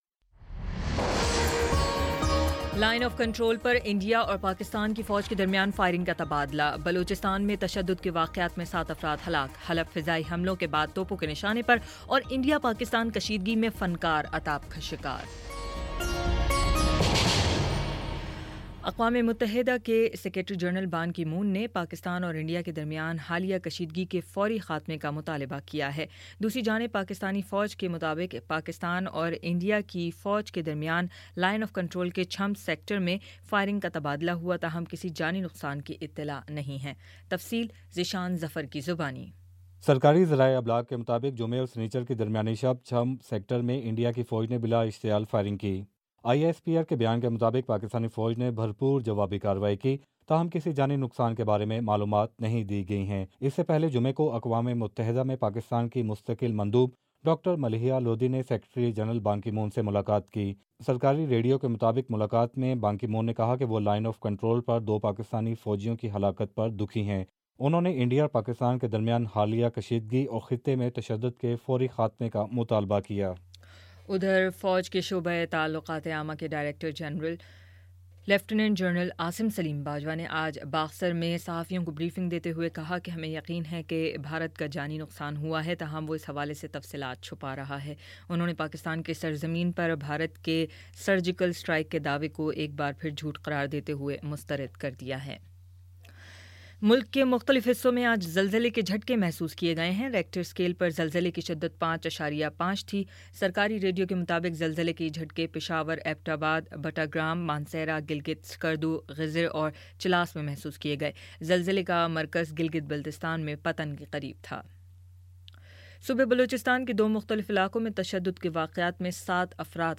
اکتوبر 01 : شام پانچ بجے کا نیوز بُلیٹن